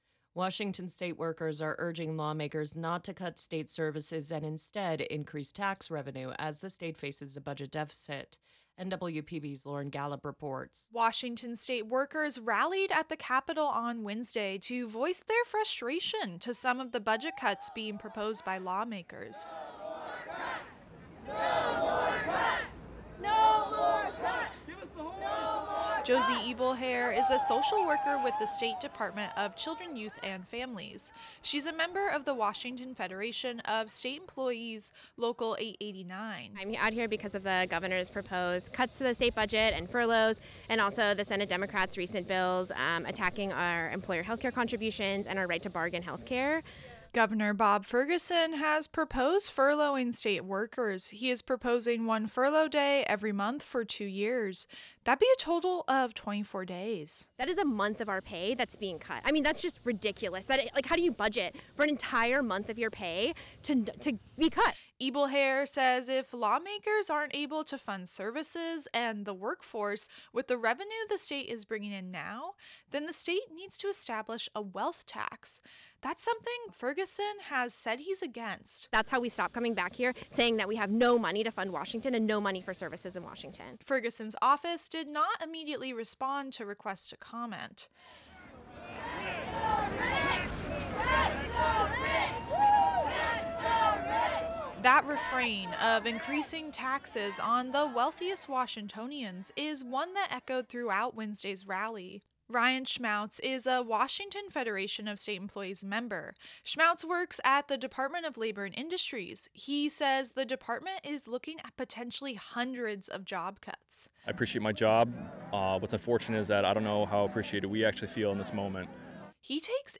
State-workers-rallly-web_mixdown.mp3